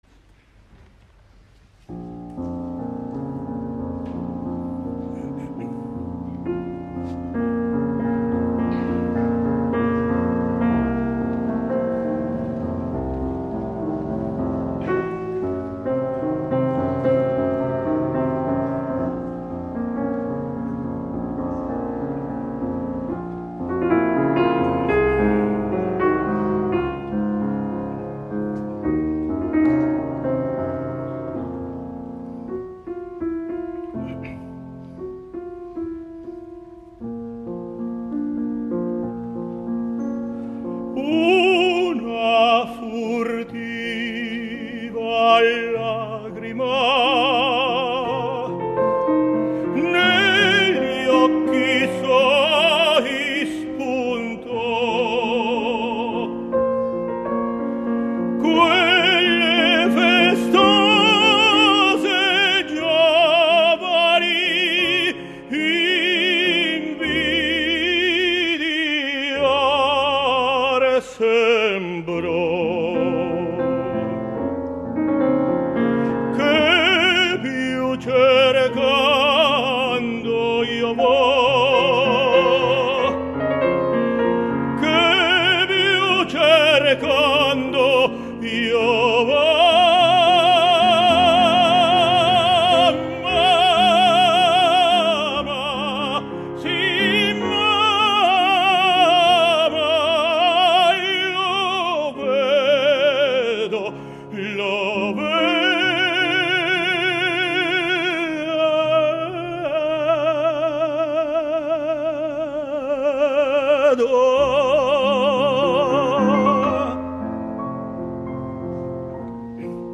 Правда, видео подпольное, с рук, но голос слышится хорошо.